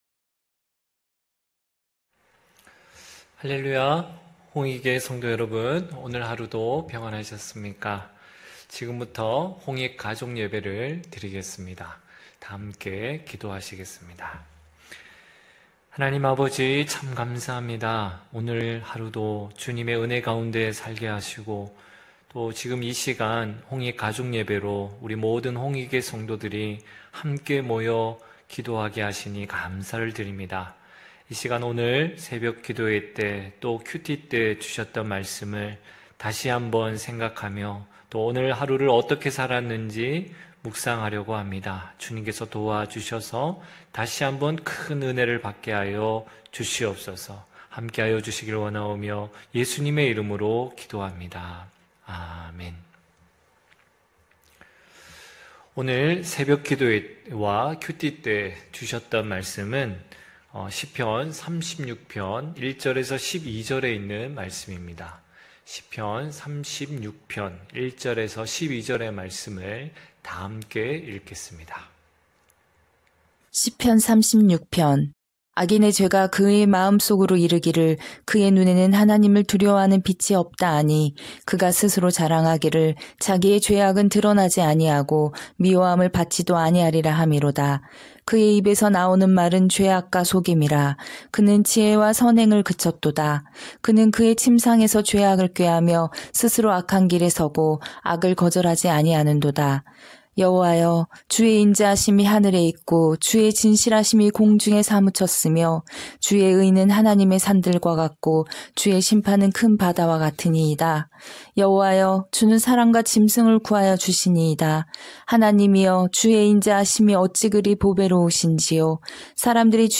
9시홍익가족예배(10월27일).mp3